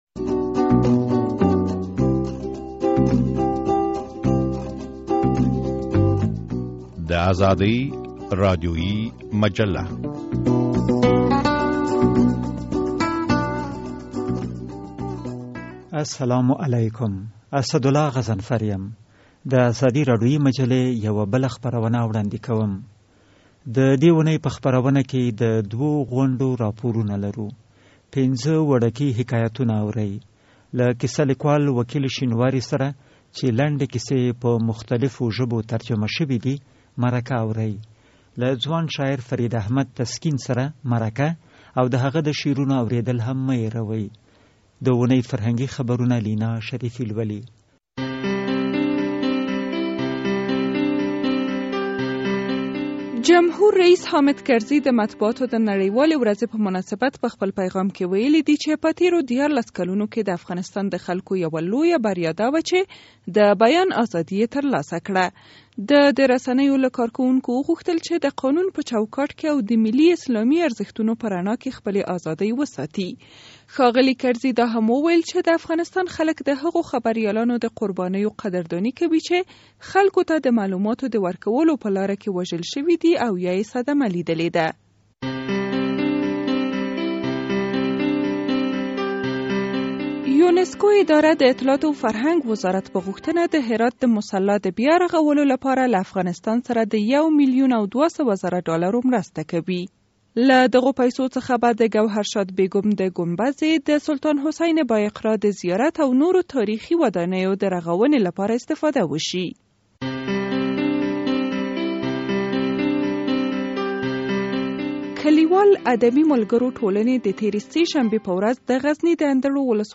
مرکه اورئ.